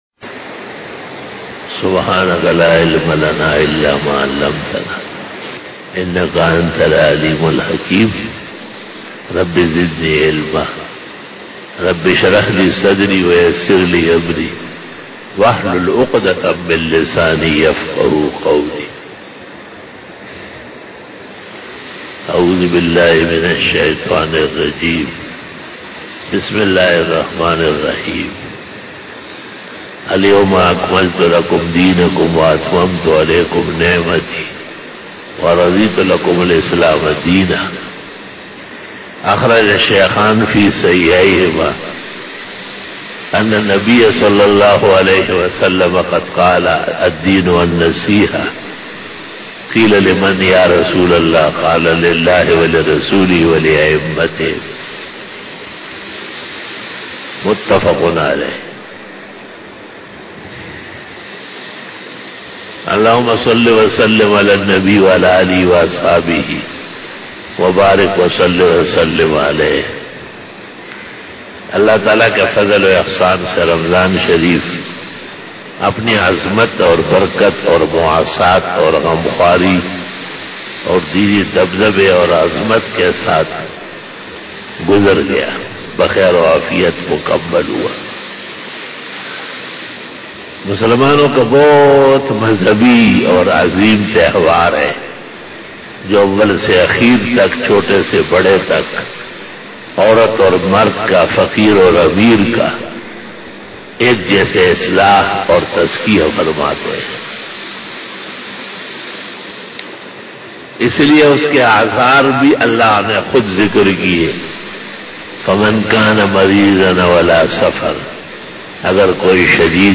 16 BAYAN E JUMA TUL MUBARAK 29 May 2020 (06 Shawwal 1441H)
Khitab-e-Jummah